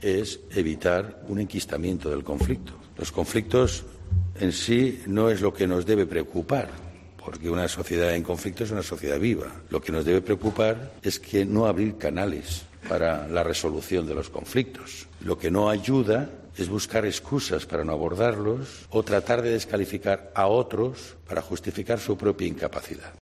En rueda de prensa en Valladolid, donde este miércoles ha repasado con el presidente de la Junta de Castilla y León, Juan Vicente Herrera, las necesidades de la Comunidad en infraestructuras, ha afirmado que no cree que el conflicto del taxi perjudique a la imagen de España.